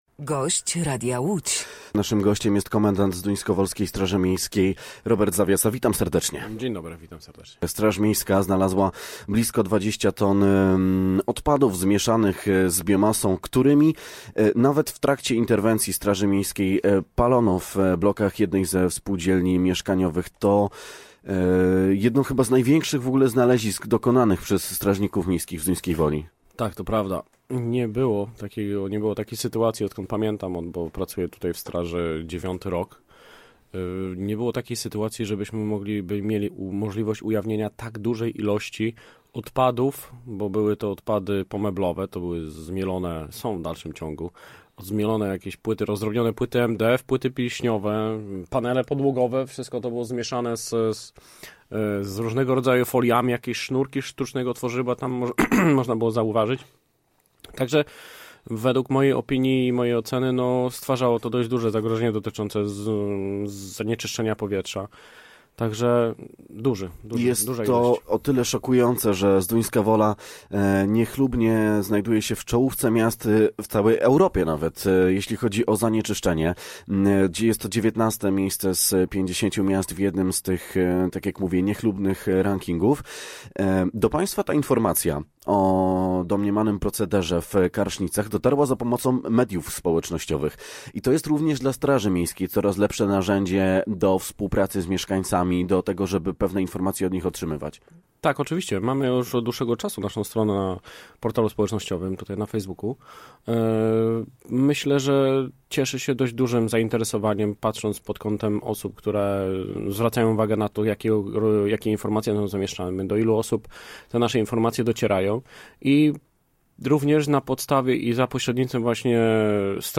Rozmawialiśmy też o akcjach profilaktycznych organizowanych przez zduńskowolską straż miejską, sposobach na walkę ze smogiem czy wizerunku strażników miejskich w regionie i w Polsce. Posłuchaj całej rozmowy: Nazwa Plik Autor – brak tytułu – audio (m4a) audio (oga) Warto przeczytać Pieniądze na sport w województwie łódzkim. 12 obiektów przejdzie remont 9 lipca 2025 Niż genueński w Łódzkiem.